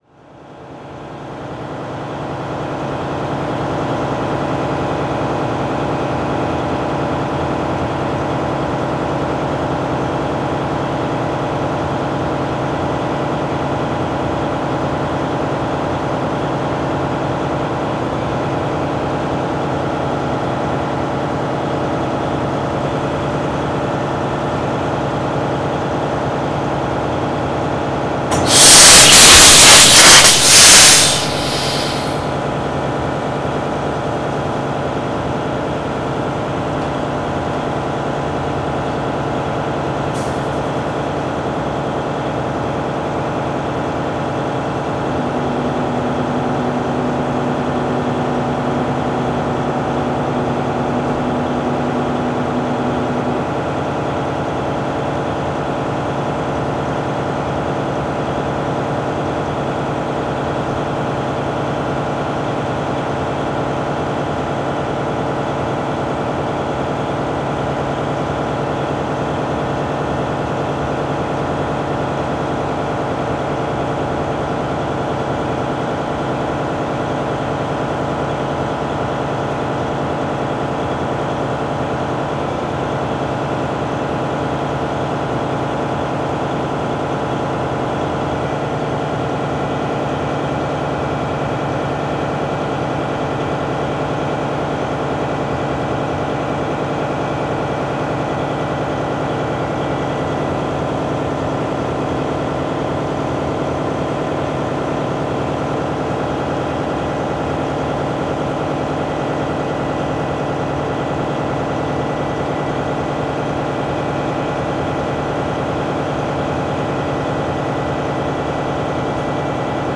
ship engine
drone engine field-recording generator loud machinery noise ship sound effect free sound royalty free Sound Effects